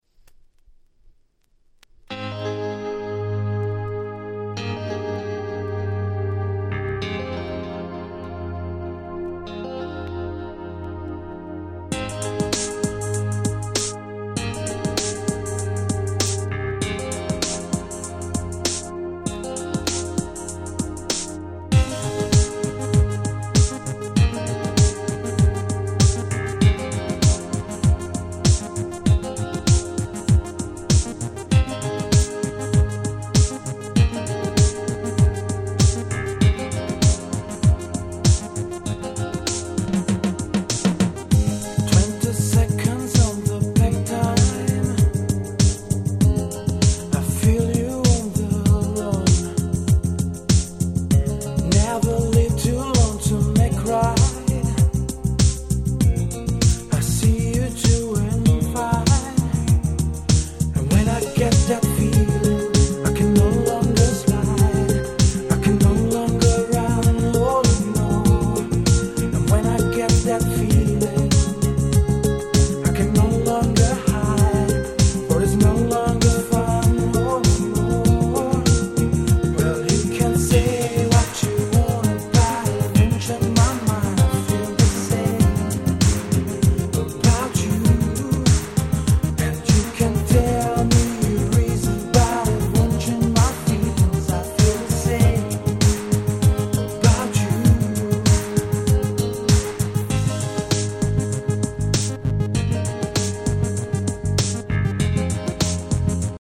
【Media】Vinyl 12'' Single (正規リプレス盤)